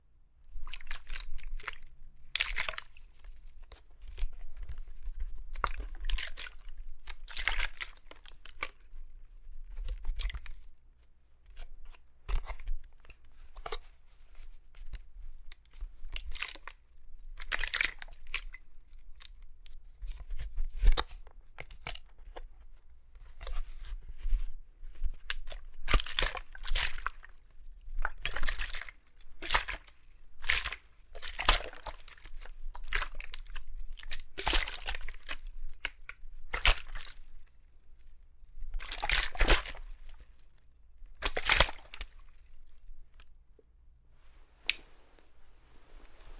随机的 " 洒水瓶处理水晃动
描述：水喷雾瓶处理水slosh.flac
Tag: 晃动 搬运 汽酒